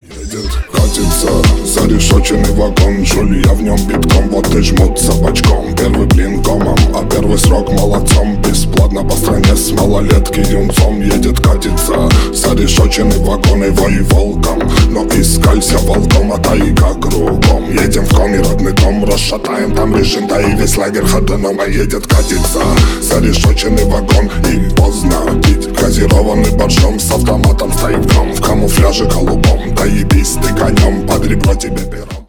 Ремикс # Рэп и Хип Хоп